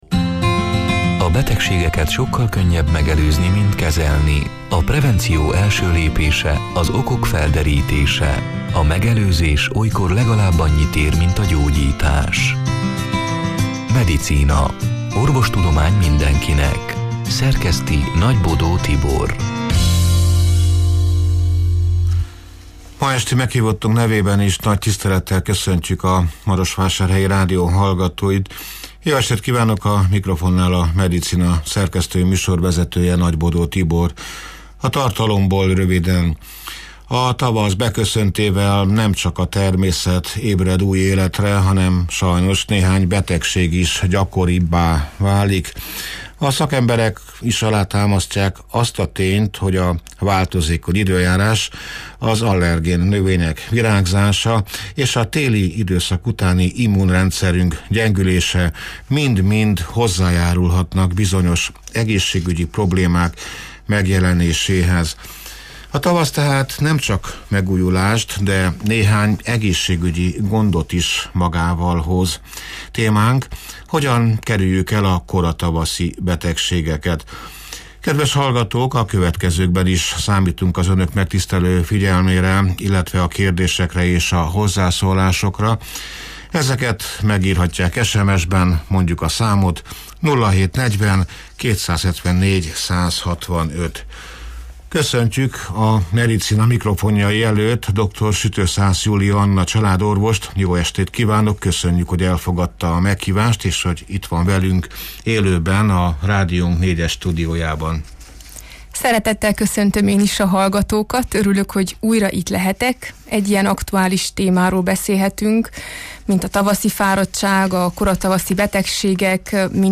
(elhangzott: 2025. március 12-én, szerdán este nyolc órától élőben)